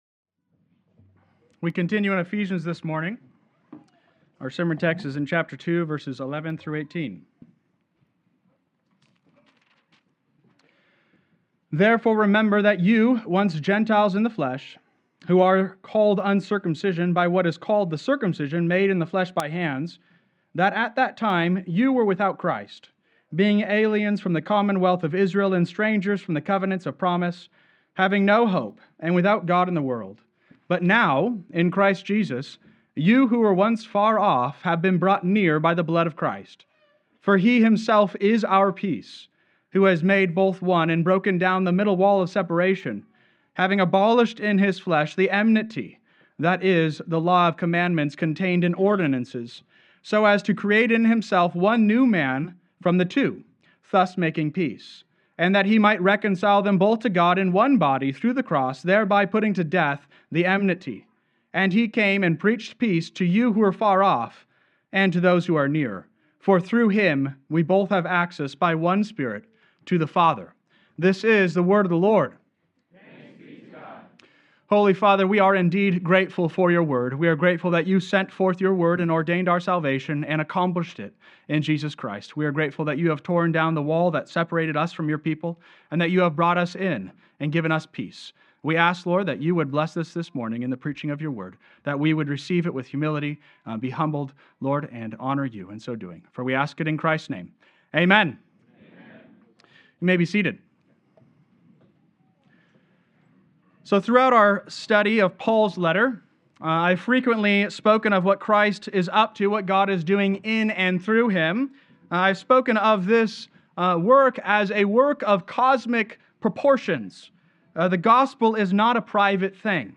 Sermon Outline: 03-23-25 Outline Ephesians 2c (Eph. 2.11-18; Prince of Peace)